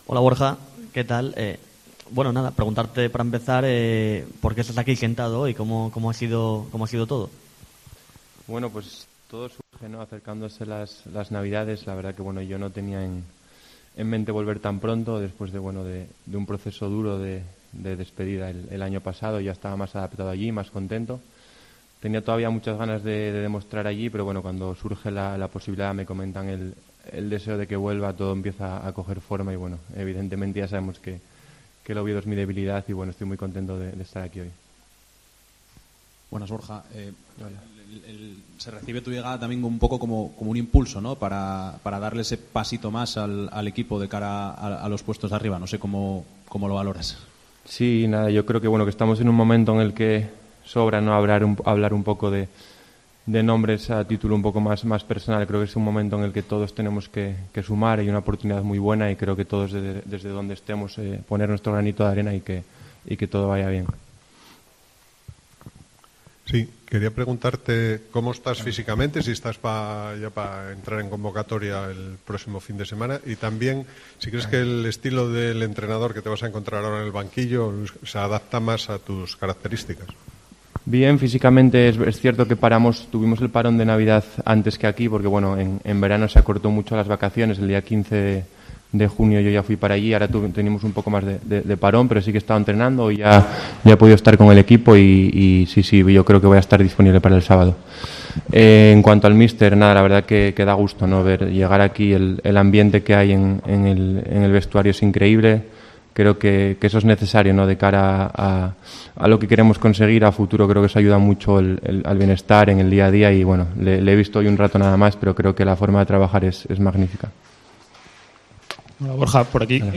PRESENTACIÓN OFICIAL
Borja Sánchez compareció ante los medios de comunicación tras hacerse oficial su regreso al Real Oviedo.